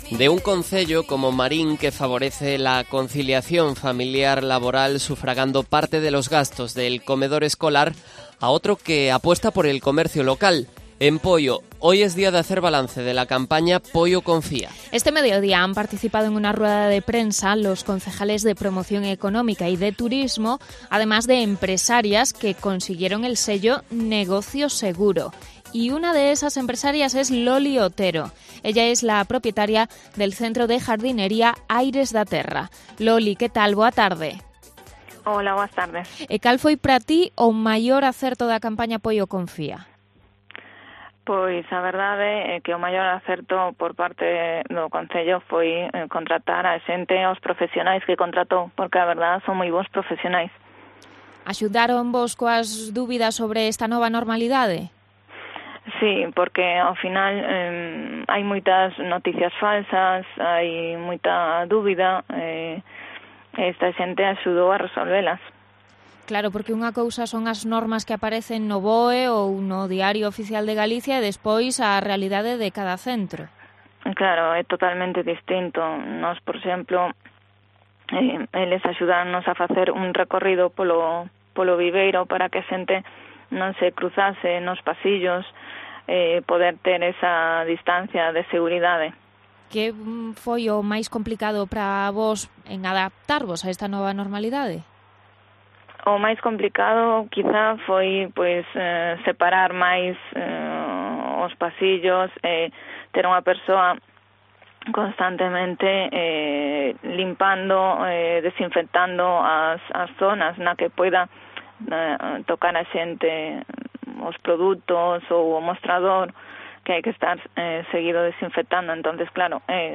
Entrevista a una emprendedora apoyada por la campaña Poio Confía